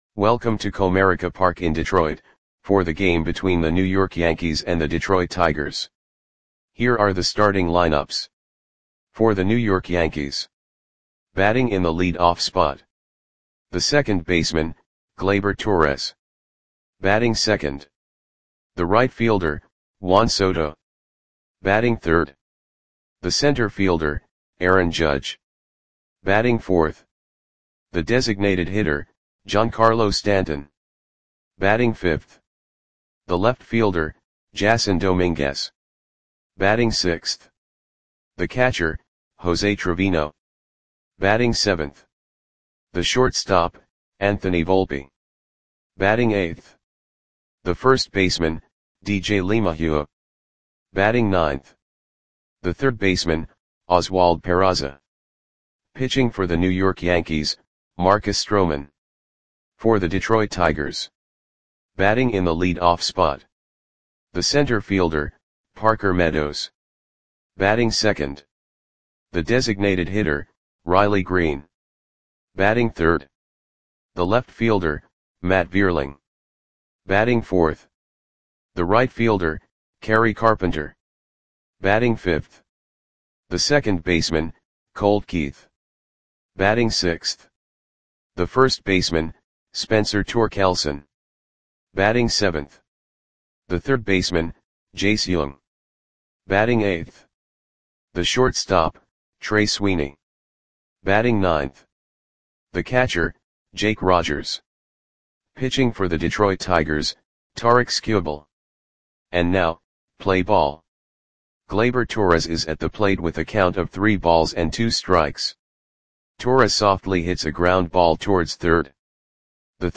Audio Play-by-Play for Detroit Tigers on August 18, 2024
Click the button below to listen to the audio play-by-play.